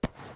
ItemPickup.wav